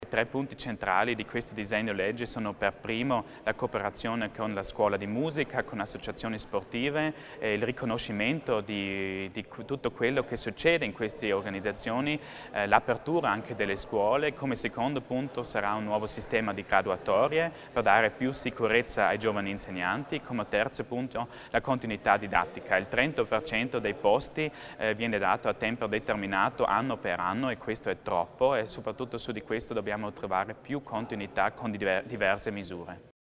L'Assessore Achammer spiega i 3 punti salienti del ddl sulla formazione